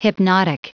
Prononciation du mot hypnotic en anglais (fichier audio)
Prononciation du mot : hypnotic